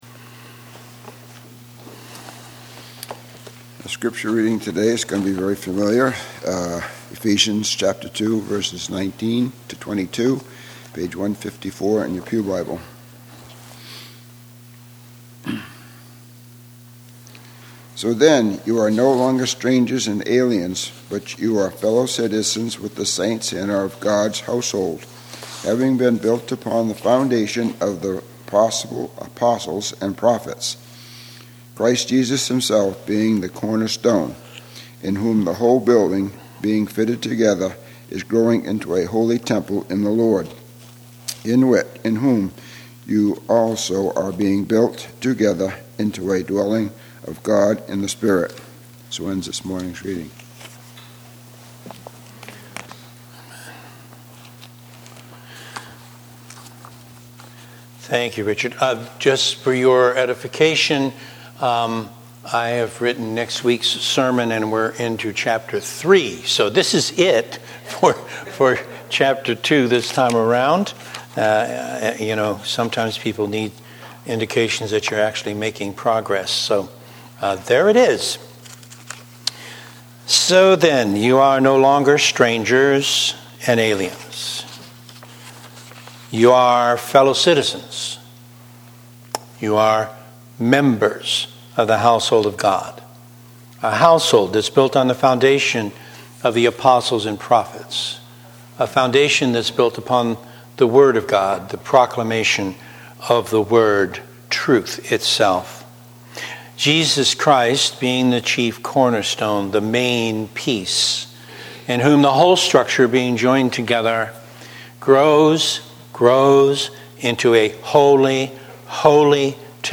Posted in Sermons